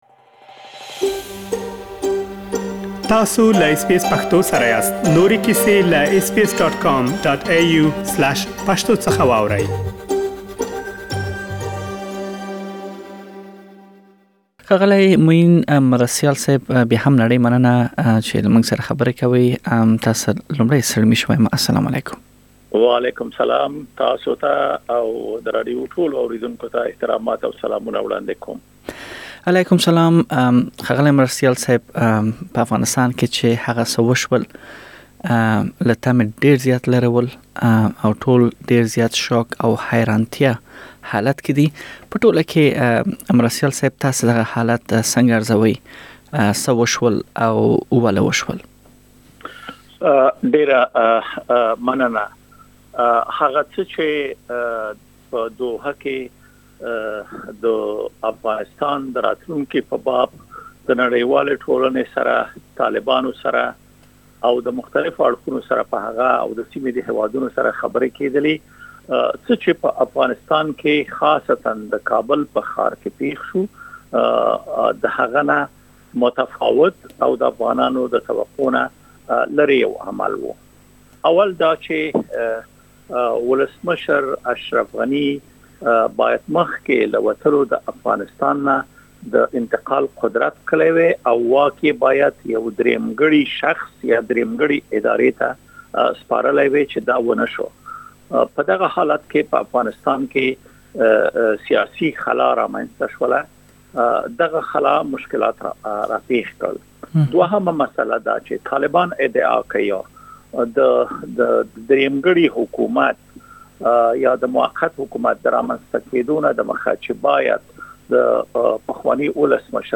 د سياسي چارو شنوونکی د پارلمان پخوانی استازی معين مرستيال وايي، که ولسمشر غني کابل کې پاتې شوی وای نو د ځينو کړيو لخوا وژل کيده. نوموړي له اس بي اس سره خبرو دوران کې وويل، طالبانو افغانستان کې واک د زور له لارې ندی ترلاسه کړی بلکې د افغان پوځ نه جګړه دوی ته قدرت په لاس ورکړ.
داچې د افغانستان پخواني حکومت ولې واک په منظم ډول طالبانو ته ونه سپاره او پخوانی ولسمشر غني د چا لخوا وژل کيدای شو، دا ټول پدې بشپړې مرکې کې واورئ.